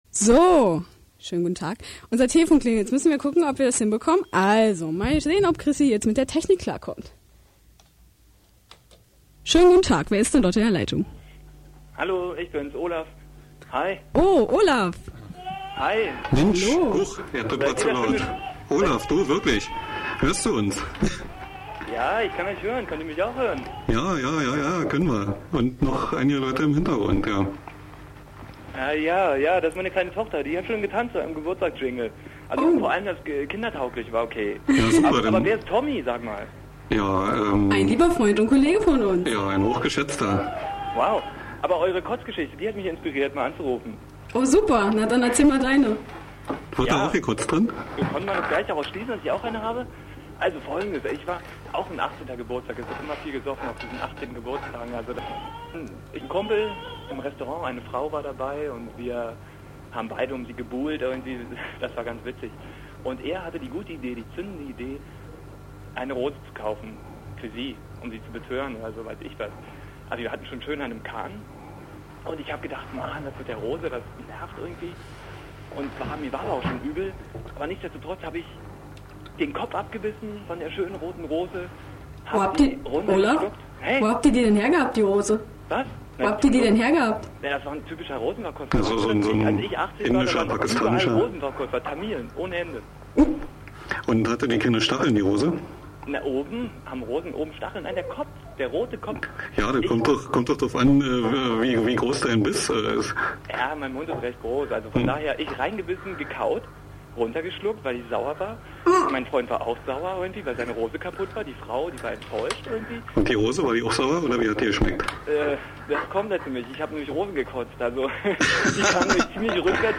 Unser erster Anrufer